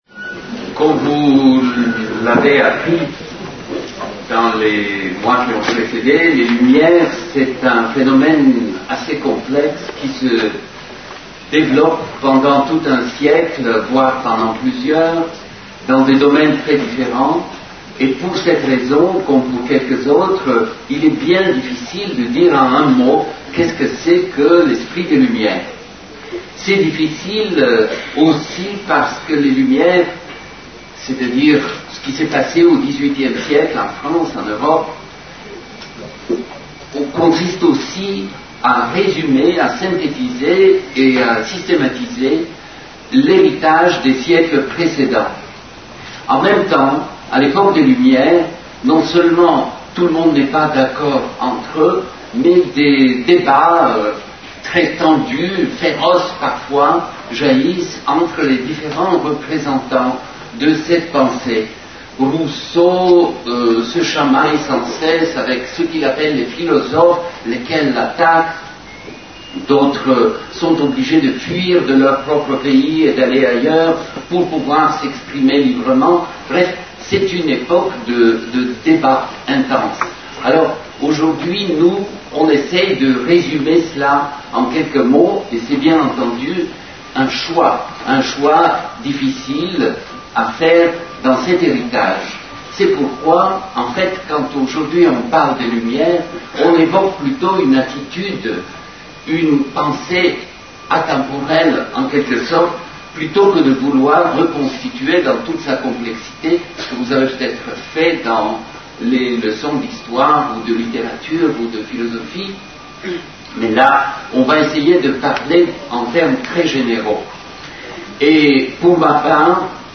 Une conférence de l'UTLS au lycée (Conférence en audio uniquement) Lycée Saint Exupéry (13015 Marseille) Avec Tzvetan Todorov (historien et philosophe) et Yves Michaud (philosophe)